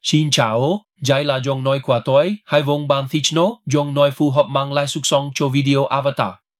🌍 Multilingual👨 Мужской
Пол: male